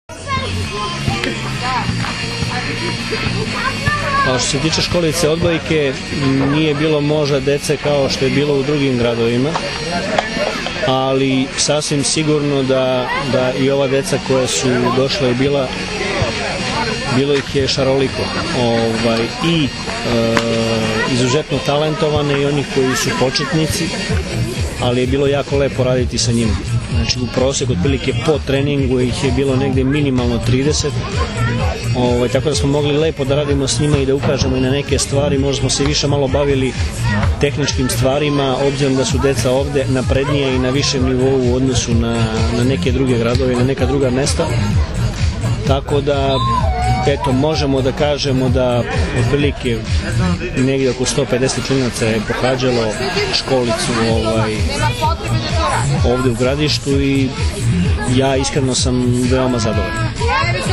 IZJAVA VLADIMIRA GRBIĆA 2